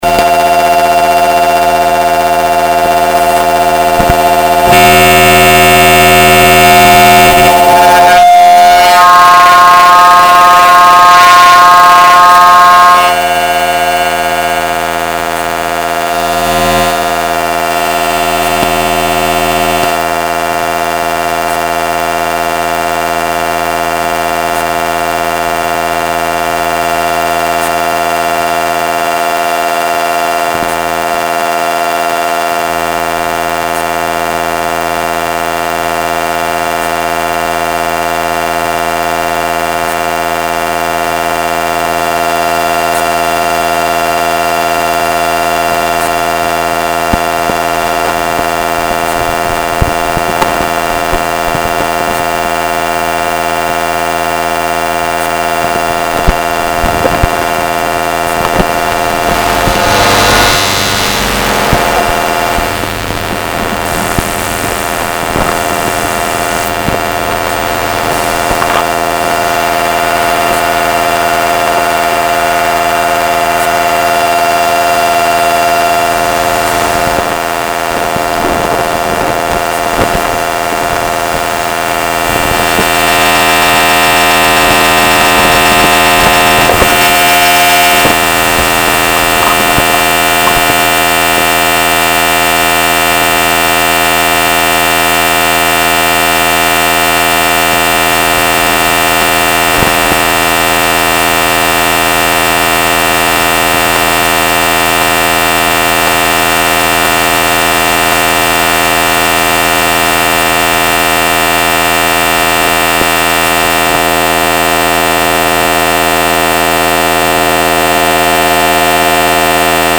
mp3 file of original wave file of sonification of electromagnetic fields encountered at the top of the Florian Funkturm in Dortmund
Funkturm_EMF_original.mp3